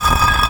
brick_m3.wav